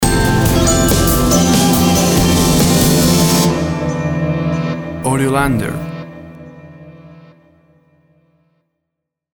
Drama and nostalgic ambient rock sounds.
WAV Sample Rate 16-Bit Stereo, 44.1 kHz
Tempo (BPM) 70